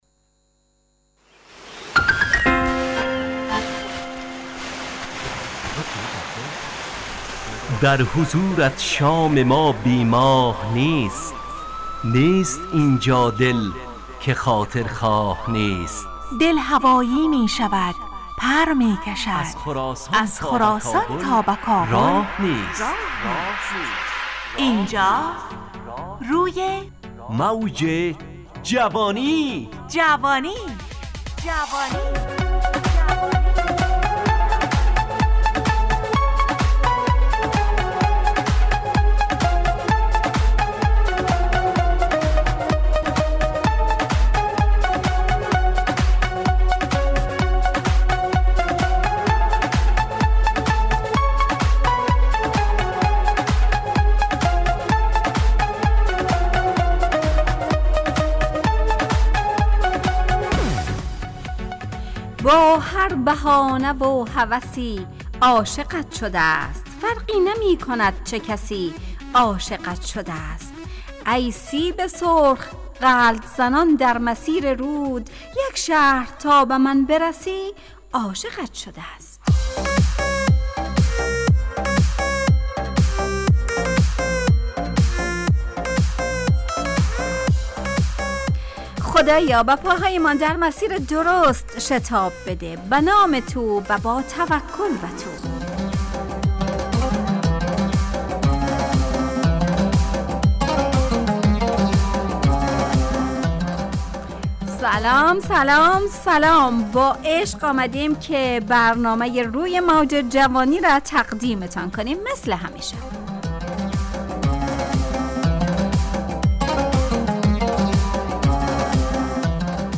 همراه با ترانه و موسیقی مدت برنامه 55 دقیقه . بحث محوری این هفته (شتاب و عجله ) تهیه کننده